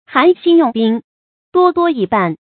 注音：ㄏㄢˊ ㄒㄧㄣˋ ㄩㄥˋ ㄅㄧㄥ ，ㄉㄨㄛ ㄉㄨㄛ ㄧˋ ㄅㄢˋ